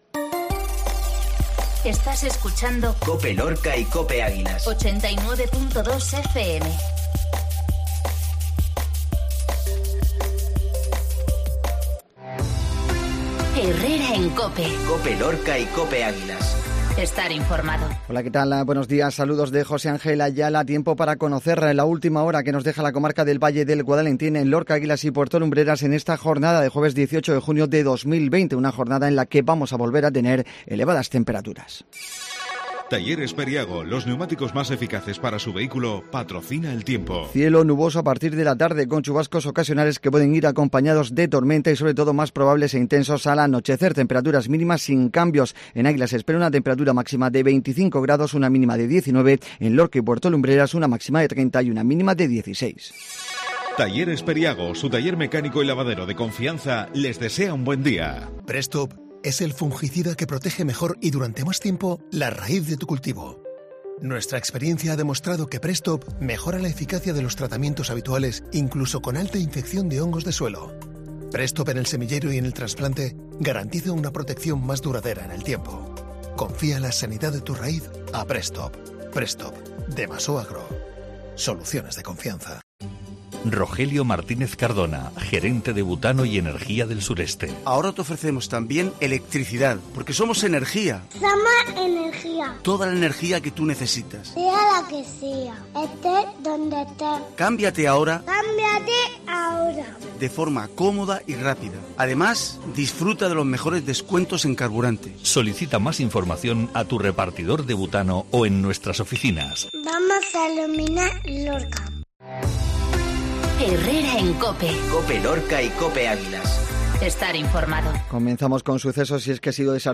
INFORMATIVO MATINAL COPE LORCA 1806